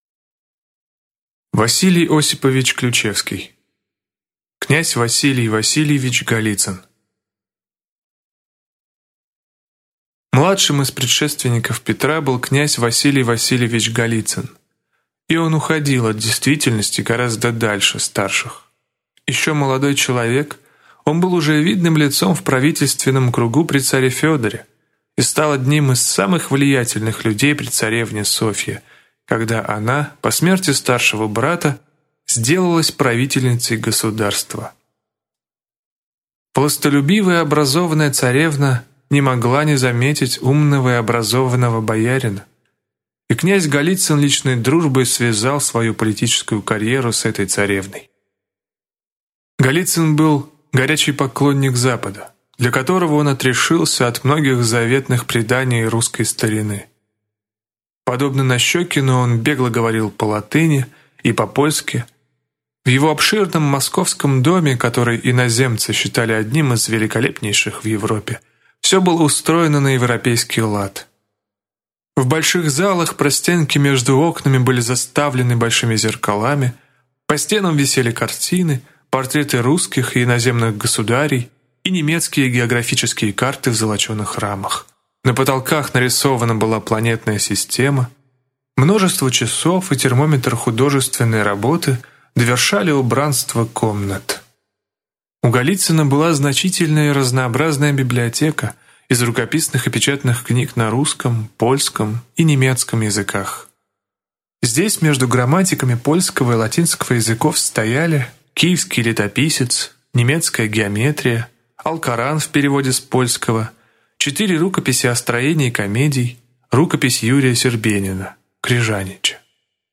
Аудиокнига Князь Василий Васильевич Голицын | Библиотека аудиокниг